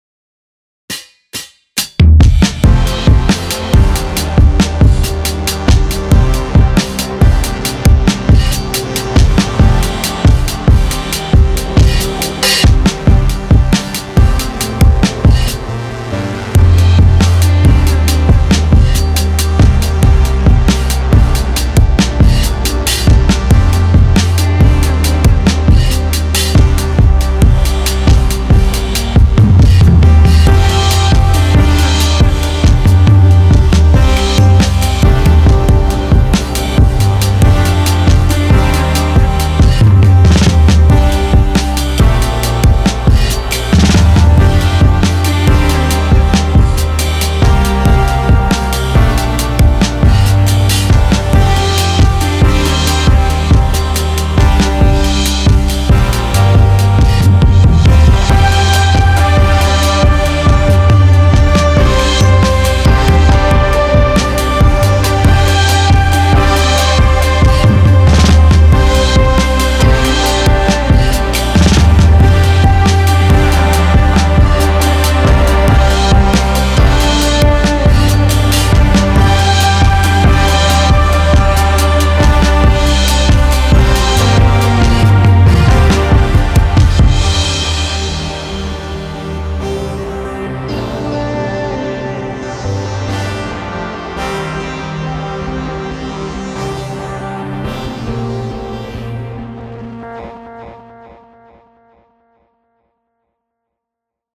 复古低保真音源 Teletone Audio Le Gibet KONTAKT-音频fun
Le Gibet 的声音是由模拟合成器、键盘和弦乐器的录音经过失真和饱和处理而制成的，可以模拟出磁带老化的温暖和浪漫。
Le Gibet 包含了超过 75 个单独的乐器和 5 个多重乐器，涵盖了各种风格和类型的键盘、垫片、贝斯、主音和合成器。